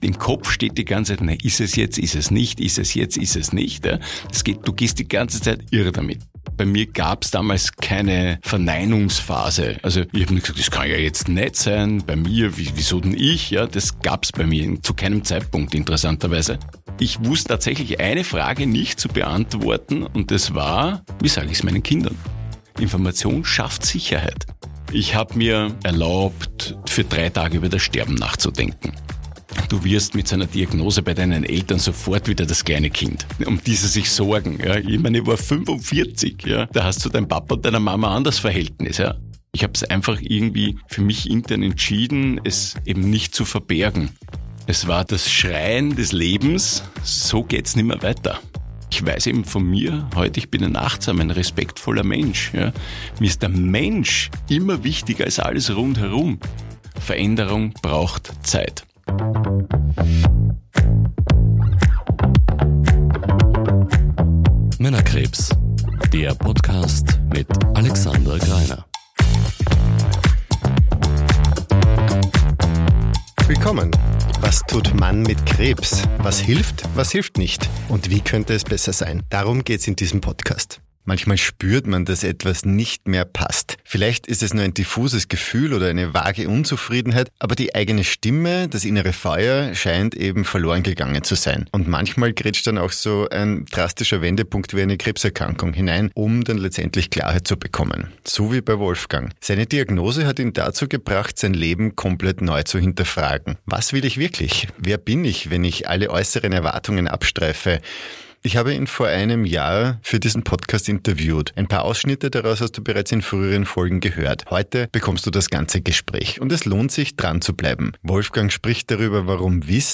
Gespräch) · Folge 12 ~ Männerkrebs – Was tut Mann mit Krebs?